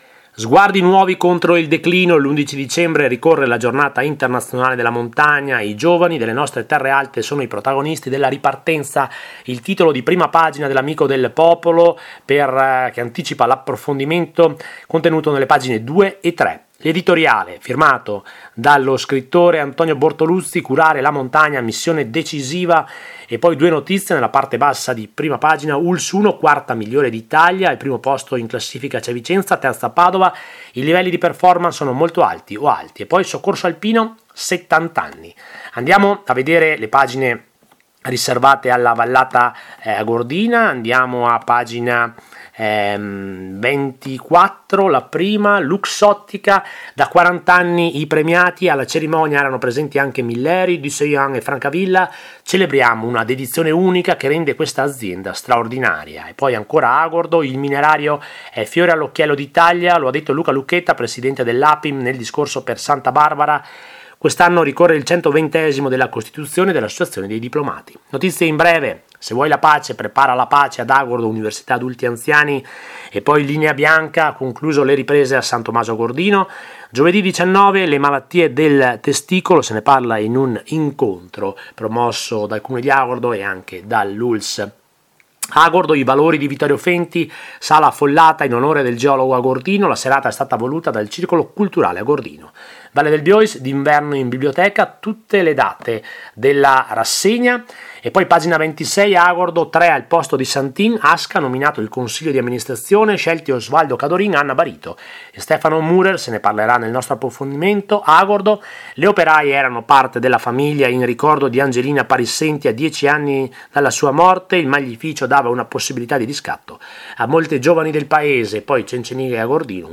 LA RASSEGNA STAMPA DELL’AMICO DEL POPOLO – 14 DICEMBRE 2024
OSPITE: Silvia Tormen, Sindaco di taibon